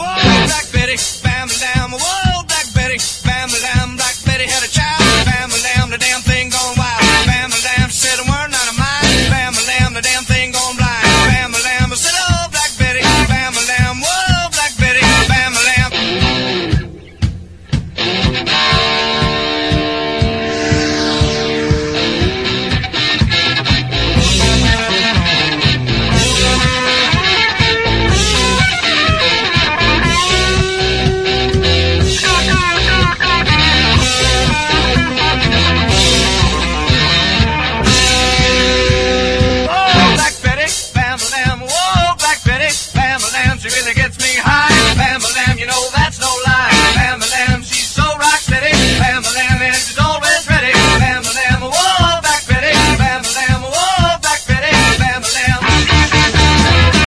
ROCK / 70'S / NEW ROCK / PROGRESSIVE ROCK